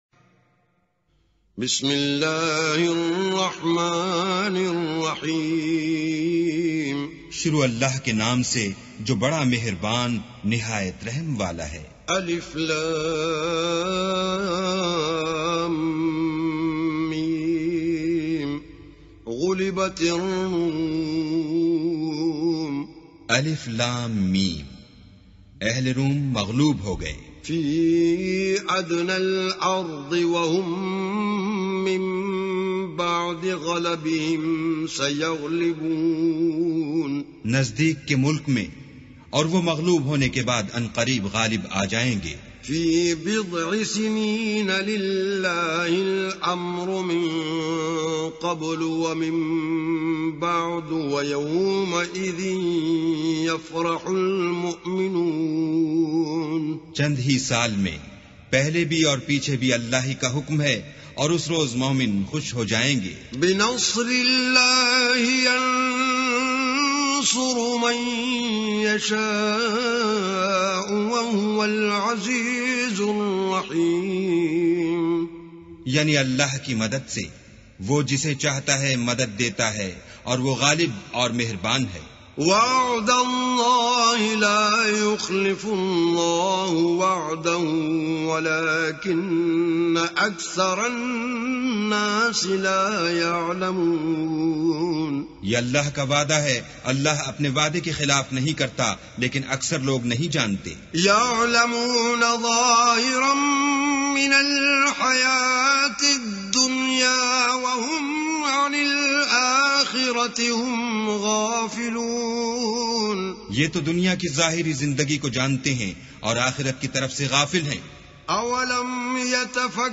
beautiful Quran Tilawat / Recitation of Surah Ar Rum